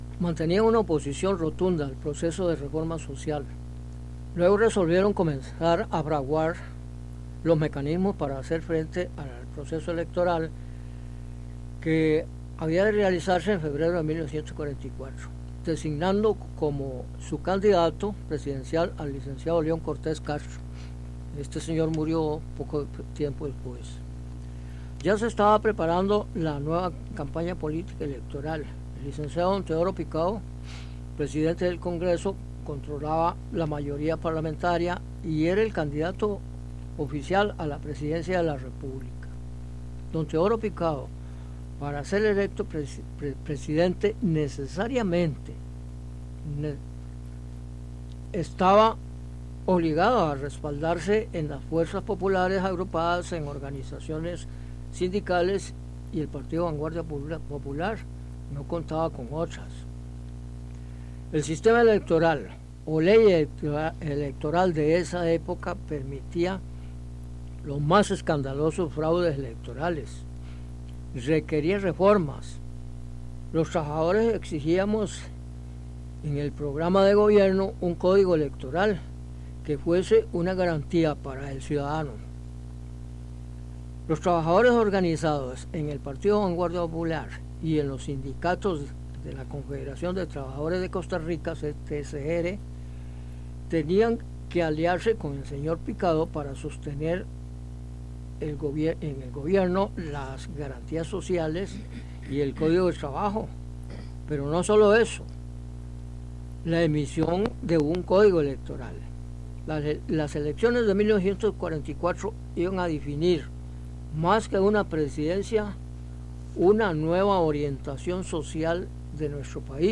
Notas: Carrete abierto, casete y digital